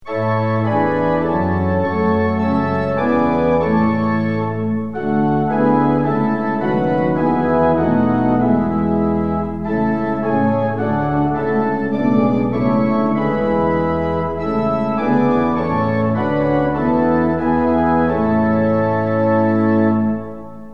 organ rendition of the tune